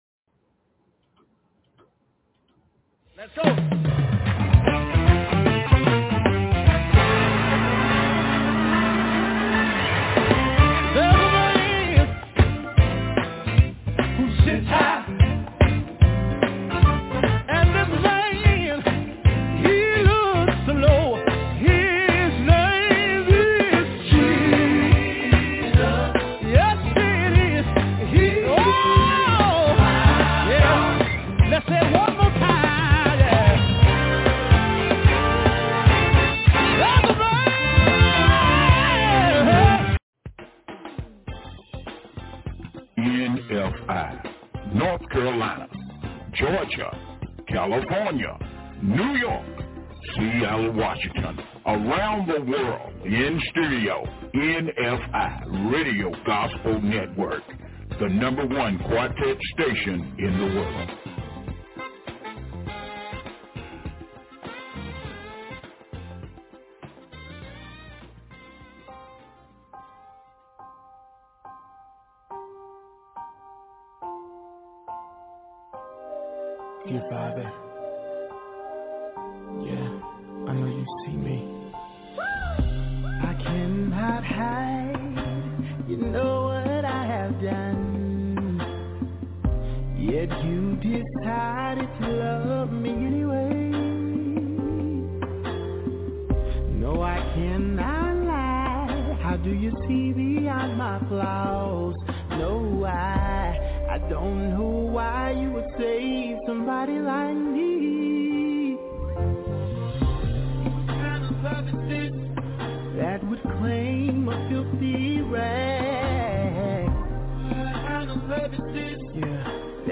LIVE 11:00am til 2:00pm